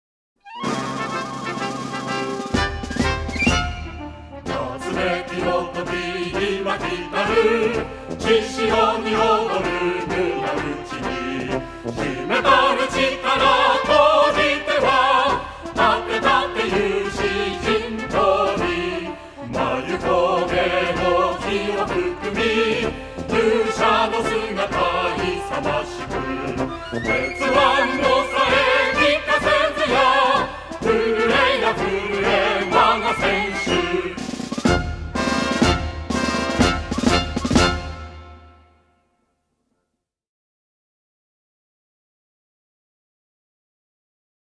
応援歌